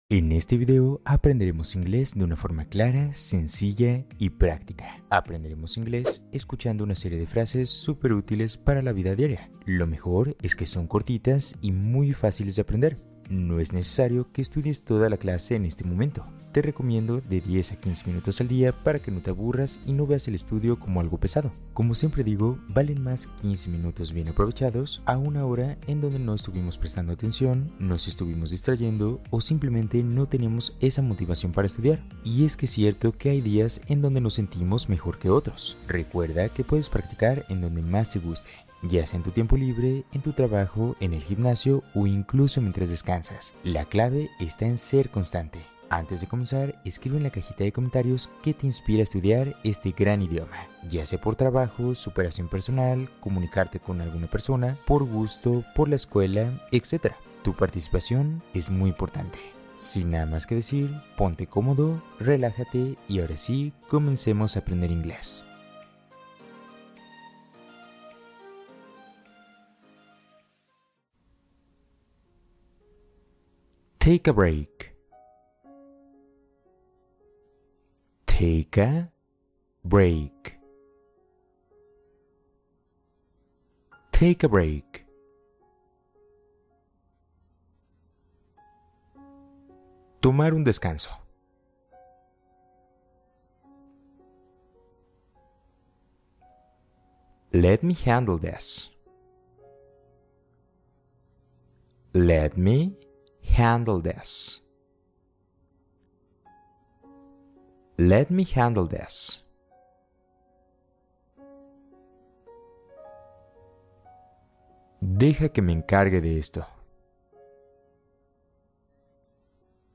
Frases lentas y claras para reforzar tu comprensión auditiva en inglés